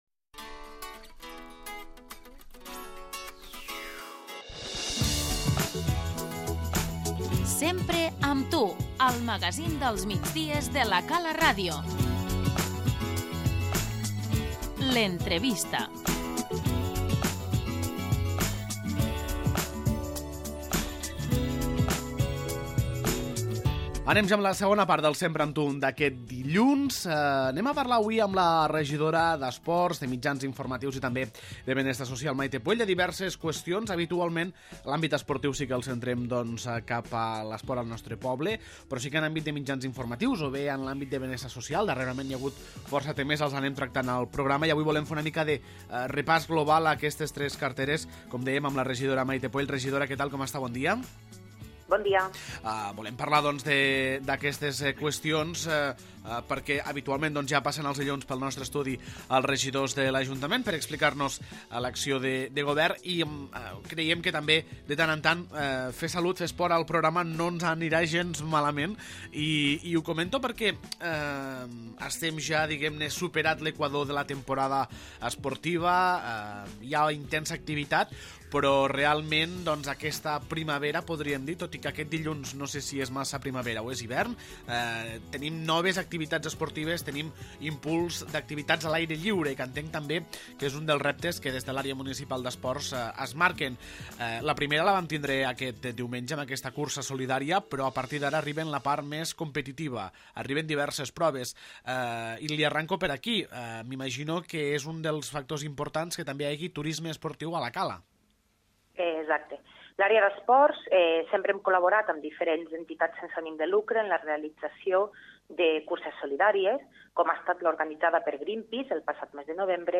L'entrevista - Mayte Puell, regidora d'Esports, Mitjans Informatius i Benestar Social